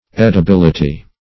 Edibility \Ed`i*bil"i*ty\, n.